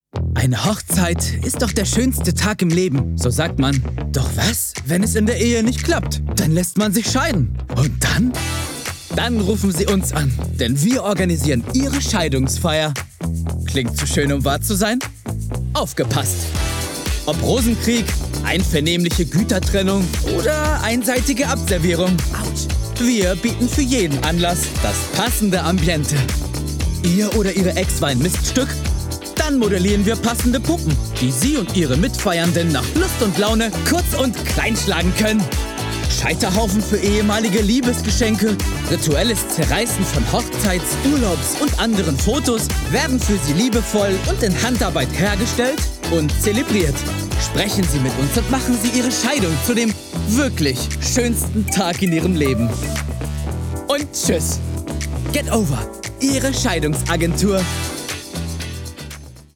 Ironisch erzählter Werbeton mit feinem Biss, lockerer Distanz und spielerischem Humor. Werbung, Marke